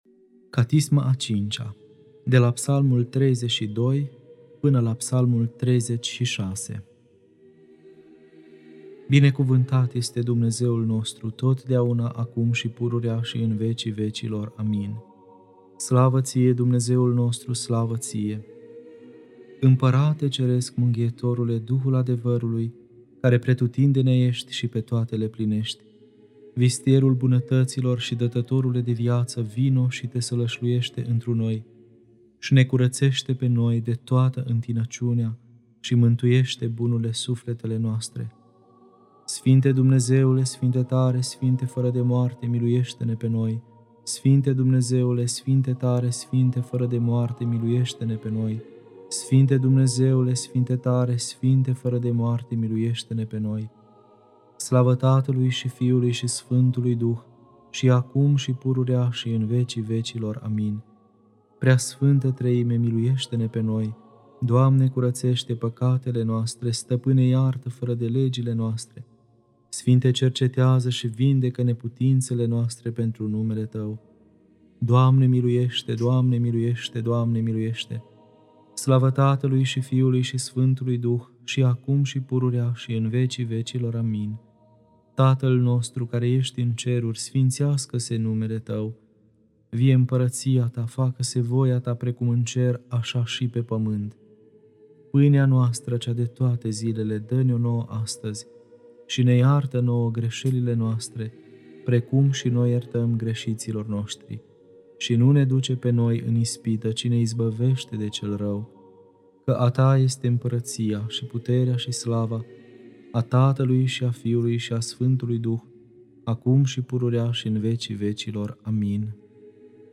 Catisma a V-a (Psalmii 32-36) Lectura